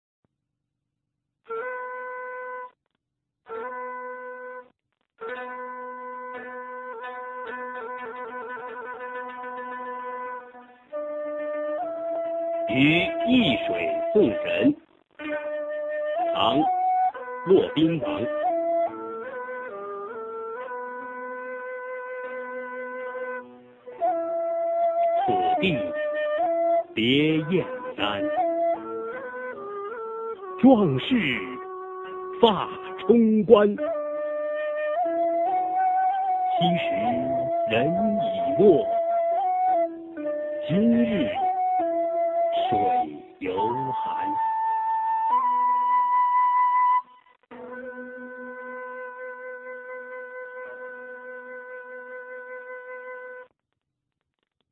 [隋唐诗词诵读]骆宾王-于易水送人a 配乐诗朗诵